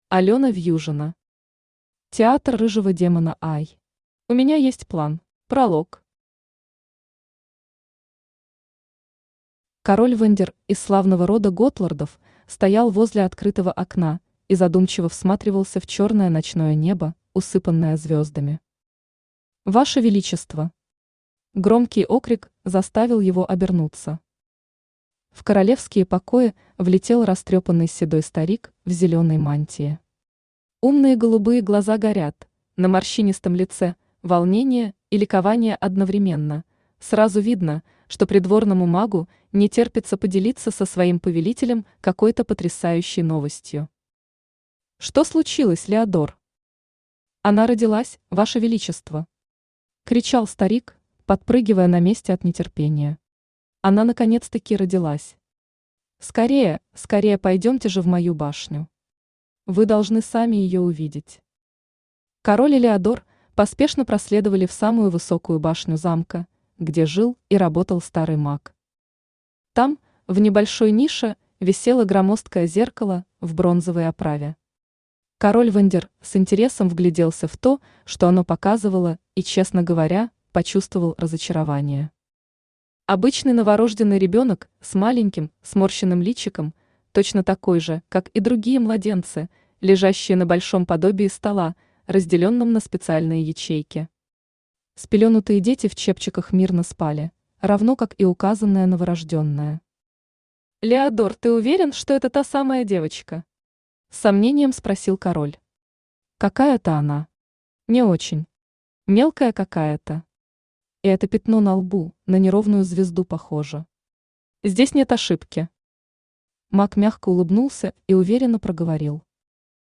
Аудиокнига Театр рыжего демона I. «У меня есть план» | Библиотека аудиокниг
Aудиокнига Театр рыжего демона I. «У меня есть план» Автор Алена Вьюжина Читает аудиокнигу Авточтец ЛитРес.